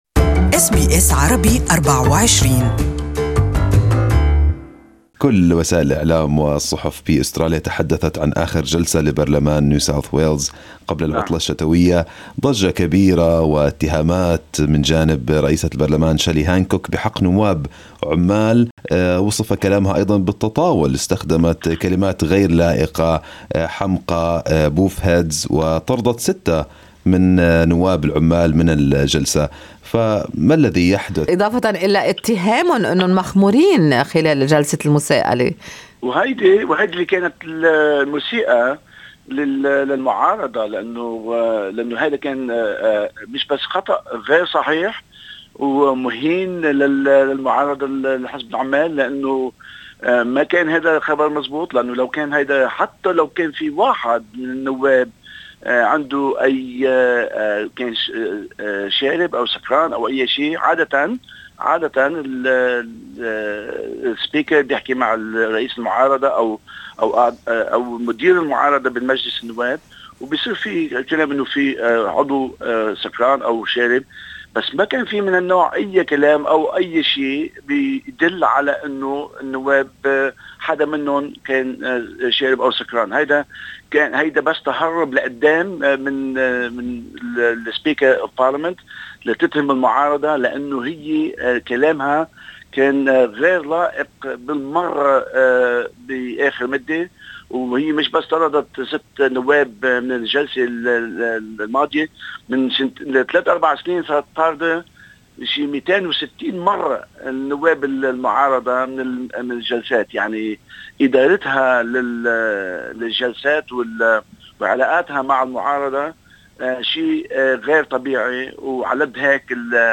Good Morning Australia interviewed opposition whip in NSW parliament, Shawkat Maslamani to discuss the official stance of Labor Party who called on the speaker of NSW parliament, Shelley Hancock to resign upon her comments labeling Labor MPs as drunk.